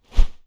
Swing On Air
Close Combat Swing Sound 13.wav